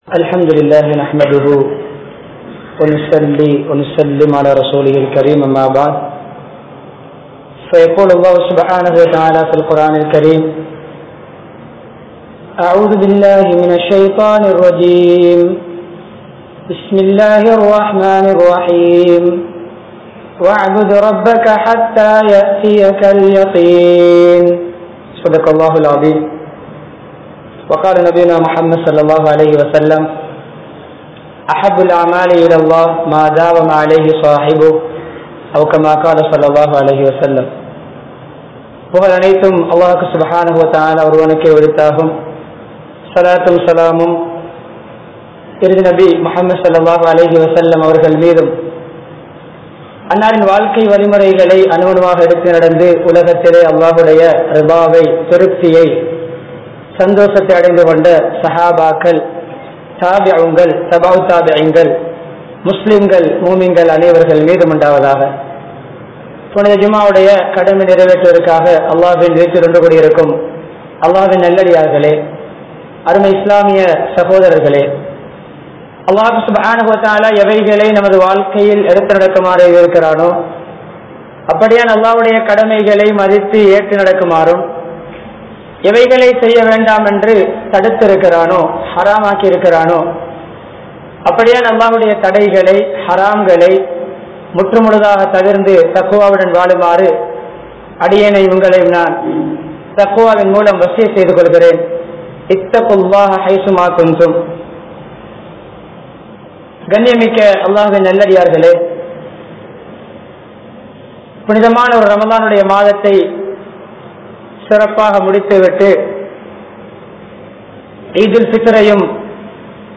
Nimmathi Ethil Undu? (நிம்மதி எதில் உண்டு?) | Audio Bayans | All Ceylon Muslim Youth Community | Addalaichenai
Majmaulkareeb Jumuah Masjith